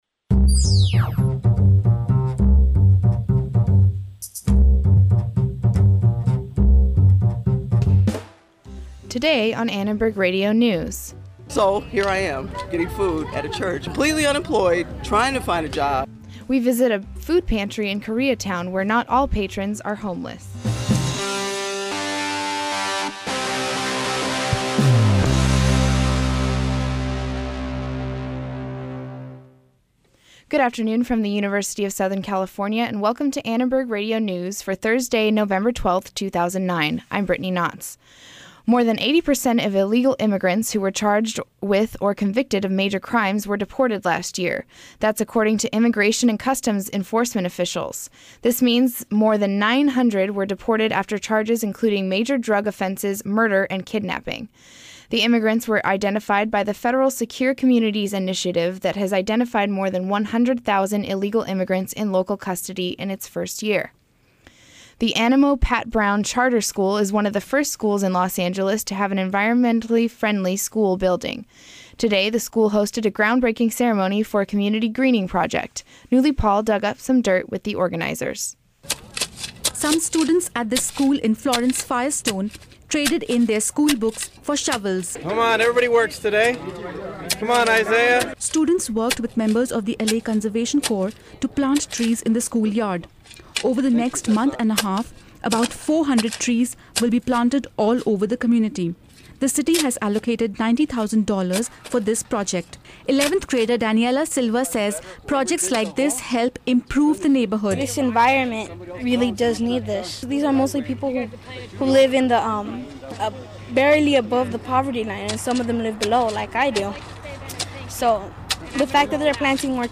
A controversial new survey says the homeless population in Los Angeles County has dropped dramatically. Listen to homeless activists who are confused and even outraged by the results, saying the numbers do not add up.
Also, listen to supporters of health care reform, who took to the streets to voice their opinions.